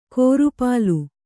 ♪ kōru pālu